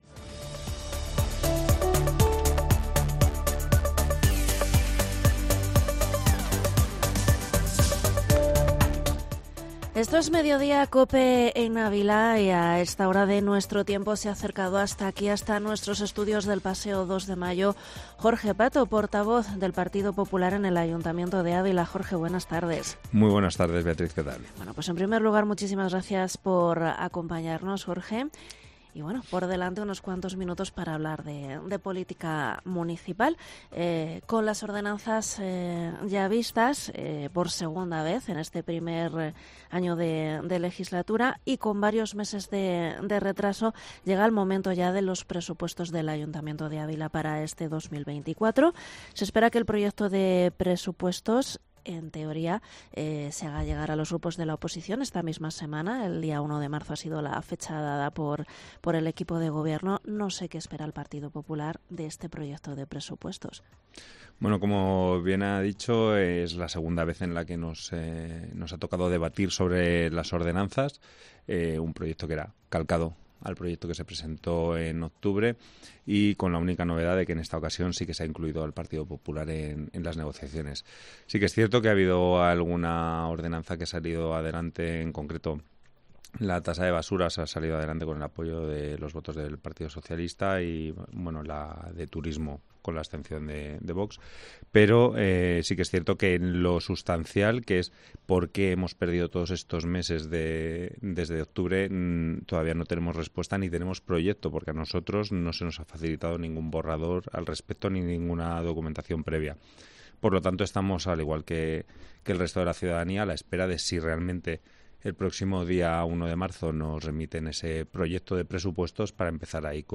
ENTREVISTA PORTAVOZ PP
Este lunes ha pasado por los micrófonos de COPE Ávila, el portavoz del Partido Popular en el Ayuntamiento de Ávila, Jorge Pato, quien a la espera de recibir este miércoles el proyecto de Presupuestos de Por Ávila para este 2024 ha adelantado su línea roja: el PP no va a apoyar “ningún recorte social”.